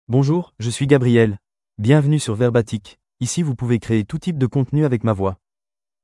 Gabriel — Male French (France) AI Voice | TTS, Voice Cloning & Video | Verbatik AI
Gabriel is a male AI voice for French (France).
Voice sample
Listen to Gabriel's male French voice.
Gabriel delivers clear pronunciation with authentic France French intonation, making your content sound professionally produced.